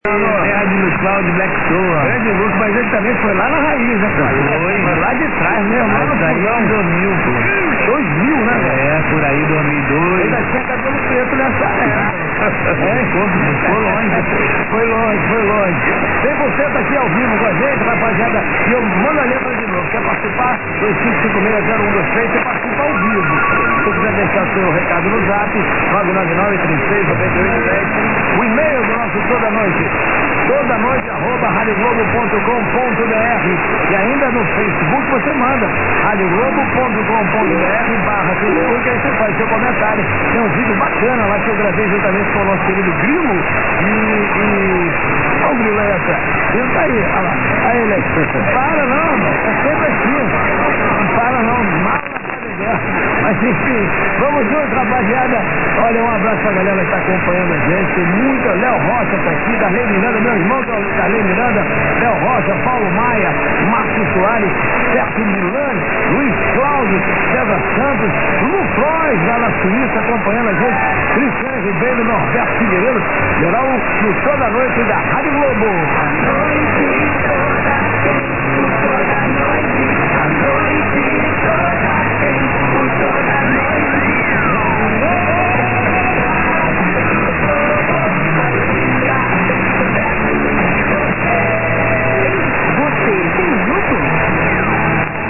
Receiver: Microtelecom Perseus
Antenna: cardioid-pattern Micro-SuperLoop on car roof, square, 2 m per side, nulling west, to W7IUV amp (gain ~ 20 dB).
1220 | BRAZIL | ZYJ258, R. Globo, Rio de Janeiro, JUN 26 0030 - Audio sweeps, Globo ID; good.